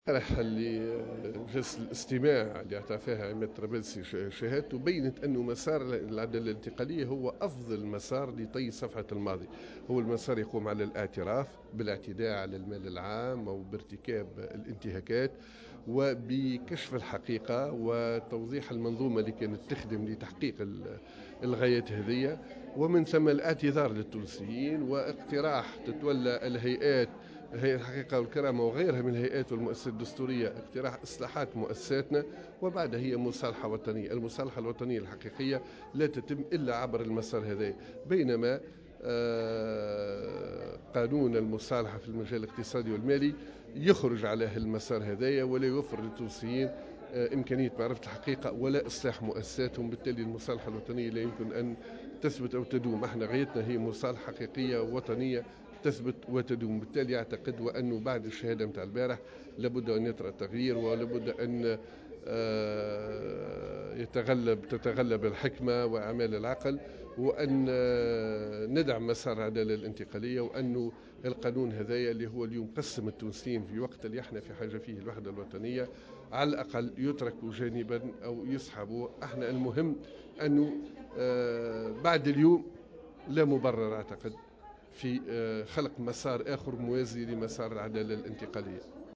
وأضاف الشابي في تصريح اليوم على هامش اجتماع المكتب السياسي للحزب الجمهوري، أن لم يعد هناك مبرّر لخلق مسار مواز لمسار العدالة الانتقالية.